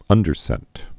(ŭndər-sĕt)